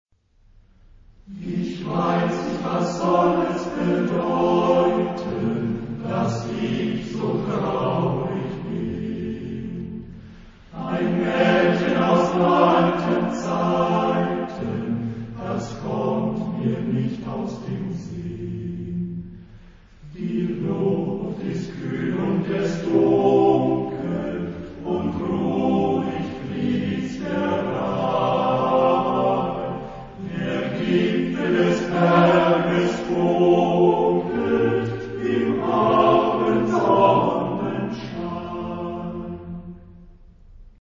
Genre-Style-Form: Folk music ; Secular ; Romantic
Mood of the piece: andante
Type of Choir: TTBB  (4 men voices )
Tonality: F major